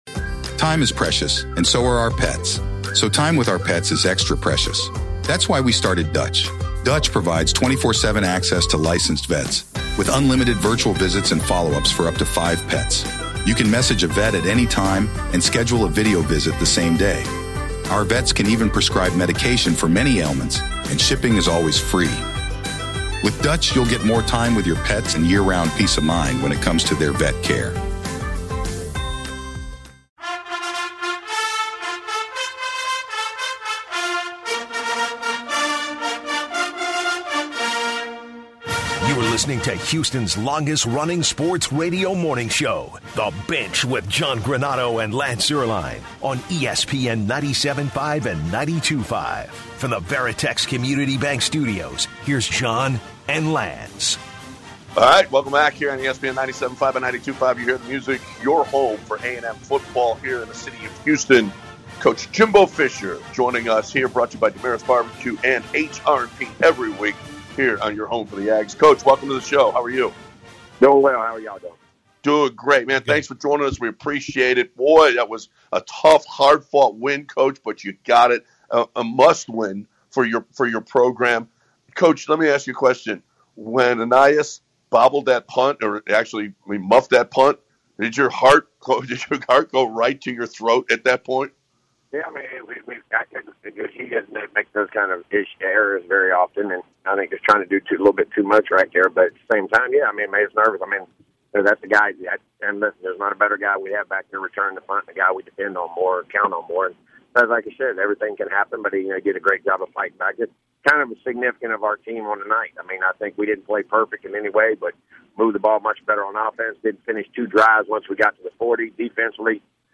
Texas A&M Head Coach Jimbo Fisher joined The Bench